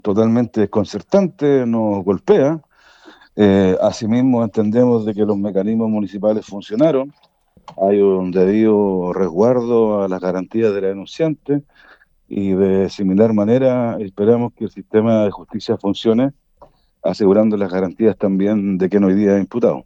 El concejal del Frente Amplio, Alejandro Bizama, se manifestó desconcertado y a su juicio funcionaron los mecanismos al interior del municipio.